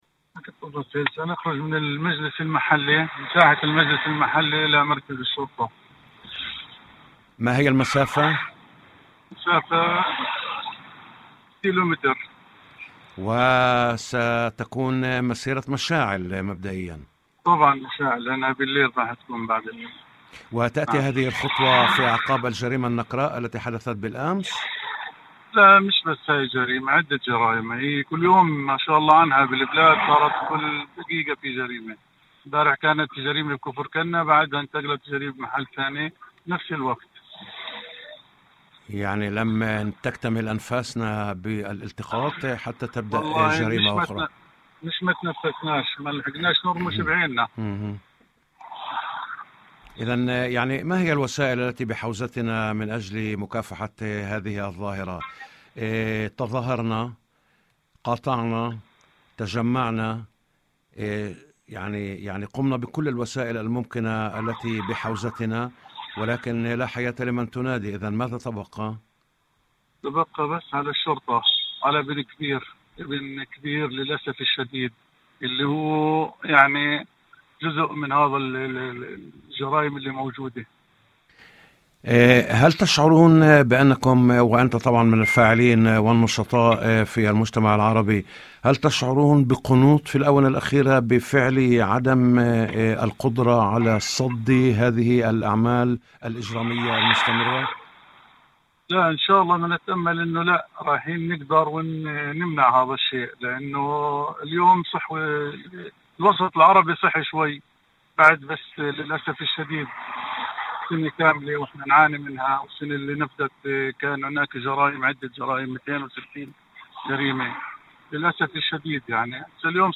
وأضاف في مداخلة هاتفية برنامج "يوم جديد"، على إذاعة الشمس، أن المسيرة ستقطع مسافة تقارب الكيلومتر، وستُقام في ساعات المساء، مؤكدًا أن هذه الخطوة لا تأتي فقط على خلفية الجريمة الأخيرة، بل في ظل سلسلة جرائم متواصلة تشهدها البلدات العربية.